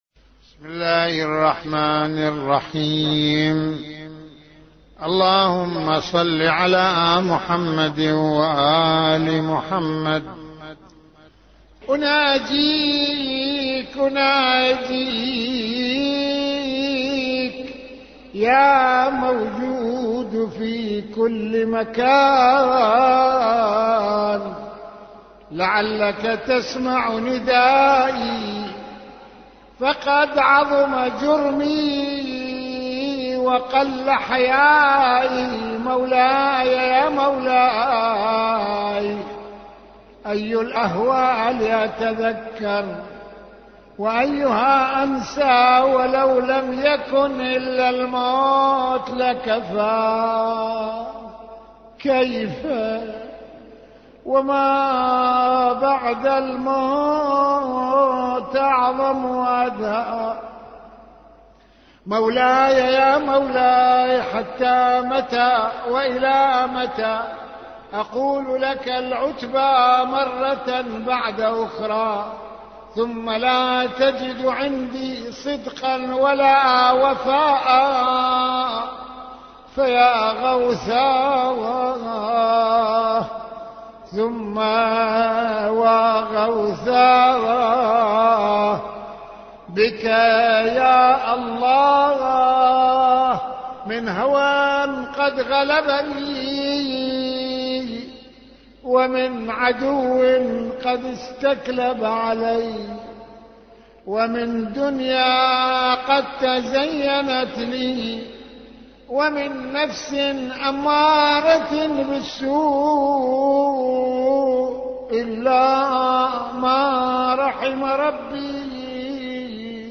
- استمع للدعاء بصوت سماحته